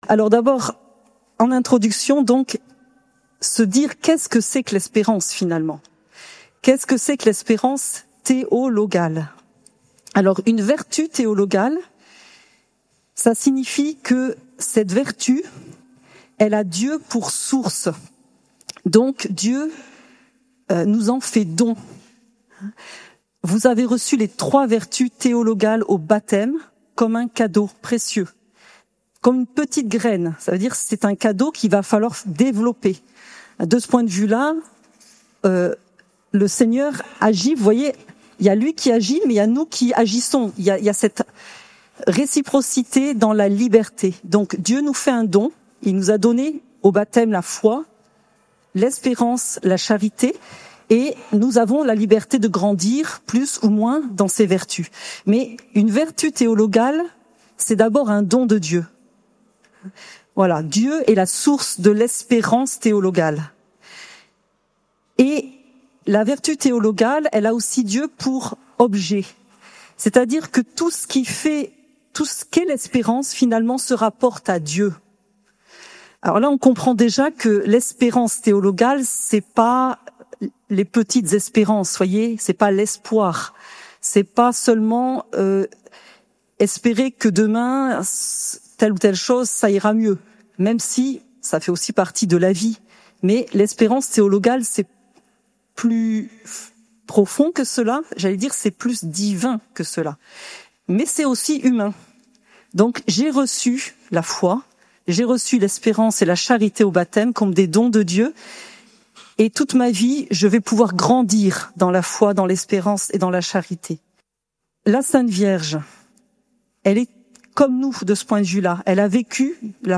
Lourdes, Pèlerinage avec la Cté des Béatitudes